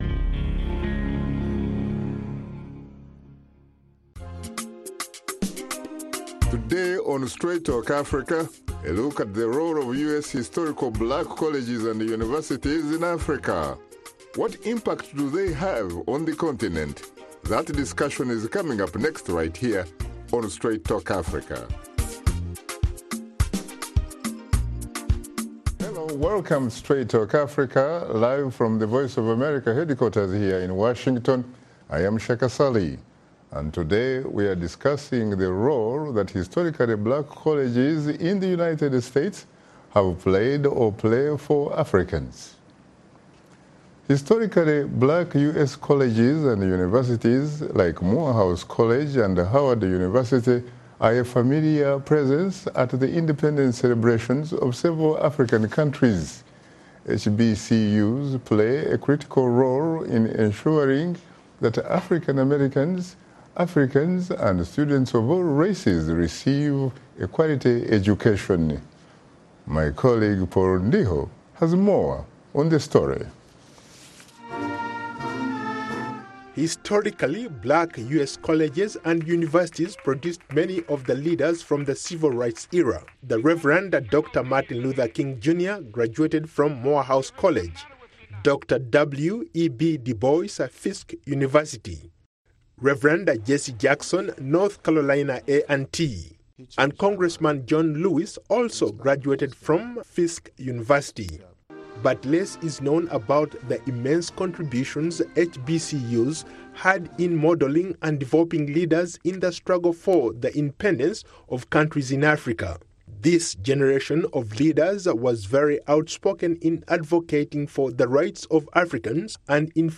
Join veteran journalist Shaka Ssali on Straight Talk Africa every Wednesday as he and his guests discuss topics of special interest to Africans, including politics, economic development, press freedom, health, social issues and conflict resolution.